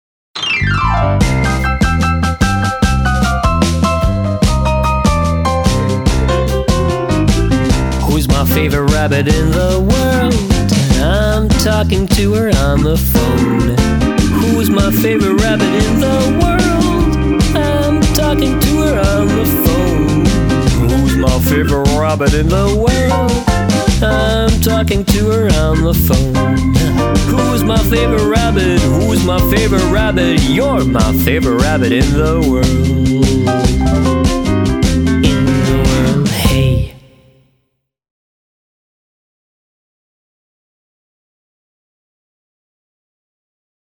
I called back and spontaneously sang this song.